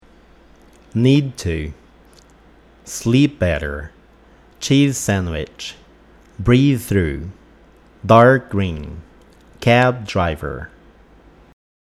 Sons vocálicos que tenham uma articulação (produção) meio parecida podem também se juntar e fazer com que duas palavras sejam pronunciadas como se fossem apenas uma.